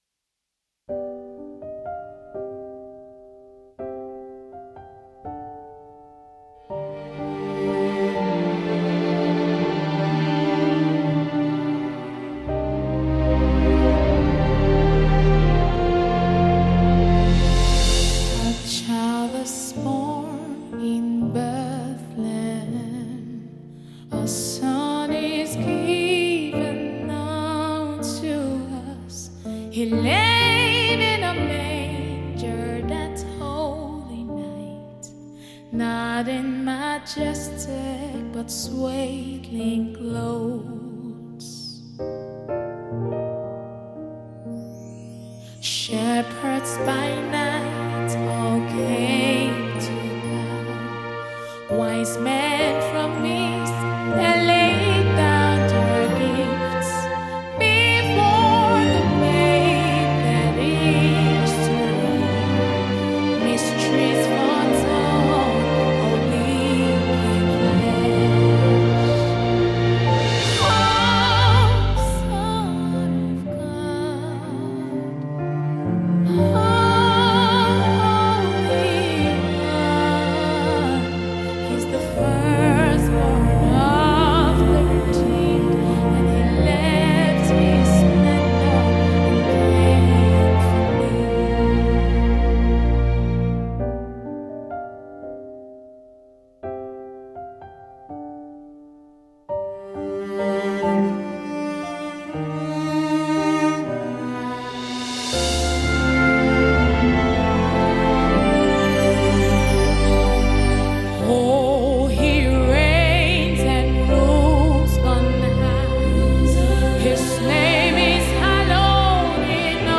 Anointed gospel musician